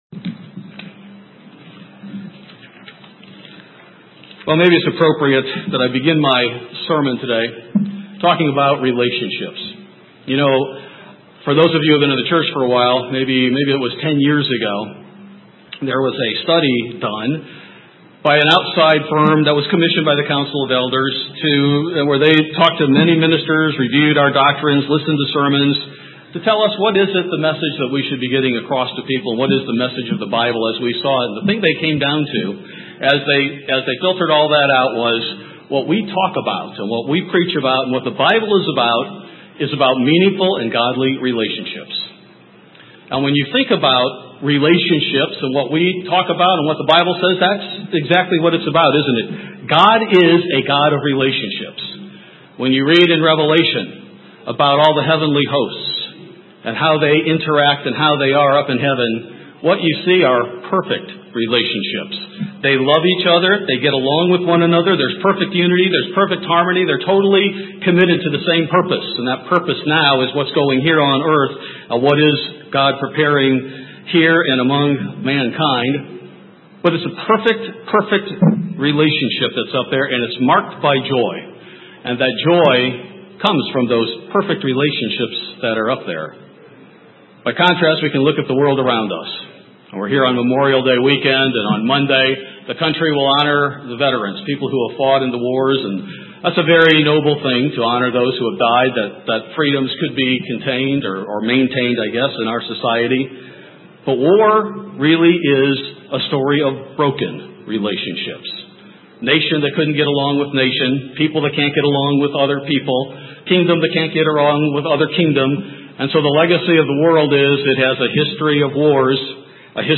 One aspect of building and maintaining relationships is reconciliation. In this sermon, we examine the examples of reconciliation in the Bible and how we can apply those steps in our lives.